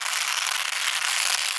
horror
Finger Nails Scratch 4